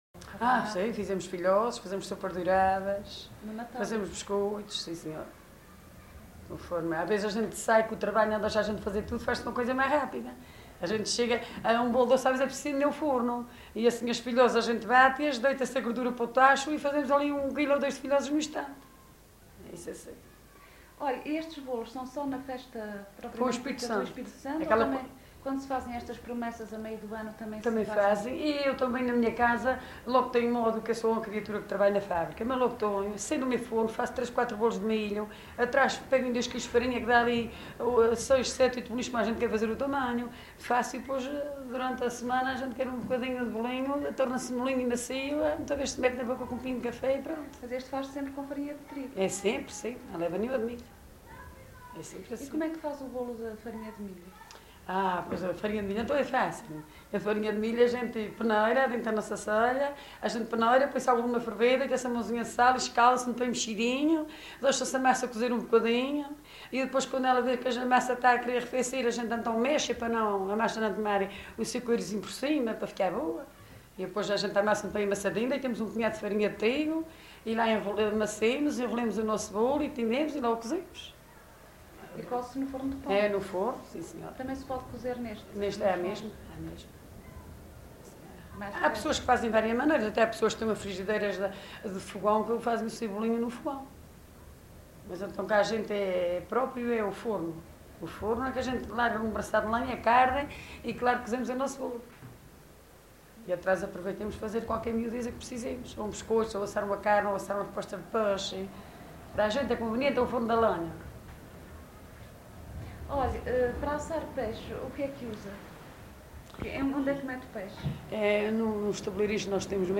LocalidadeBandeiras (Madalena, Horta)